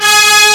HORNENSMLOOP.wav